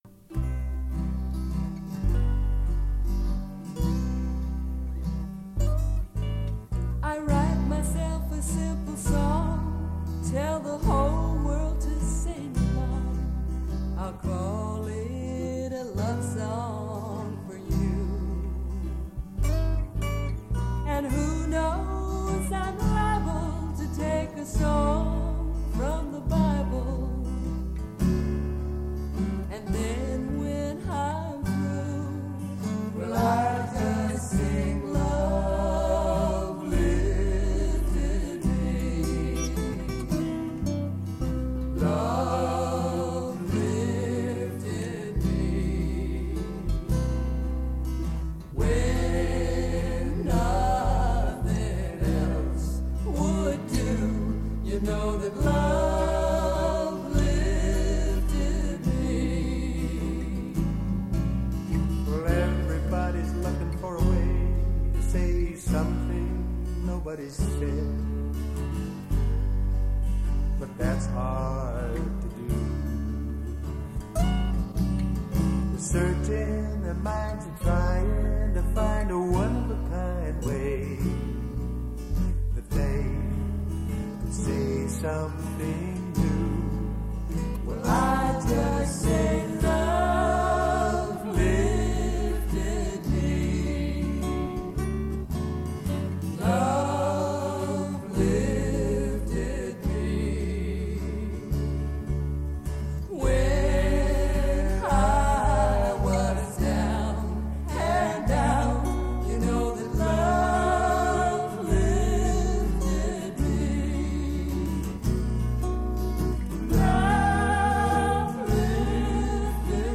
Devotional Songs
Major (Shankarabharanam / Bilawal)
6 Beat / Dadra
Medium Slow
4 Pancham / F
1 Pancham / C